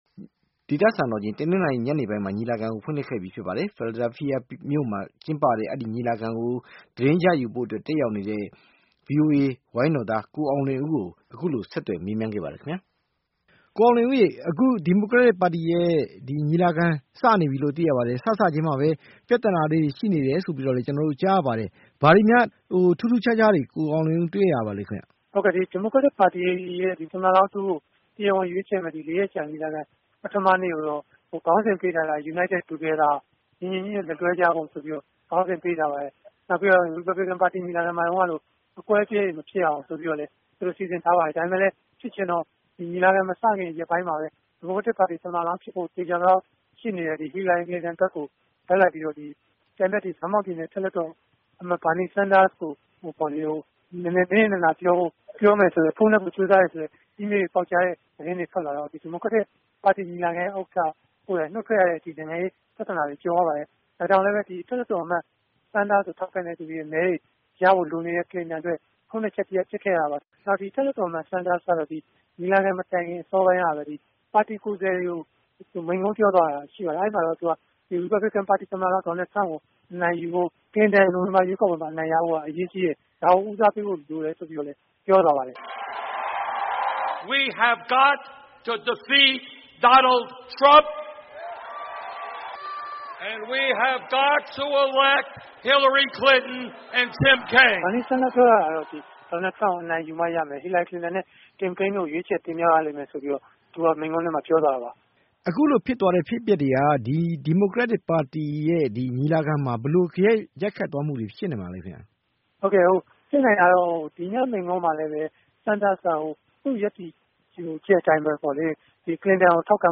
အခုလို ဆက်သွယ်မေးမြန်းခဲ့ပါတယ်။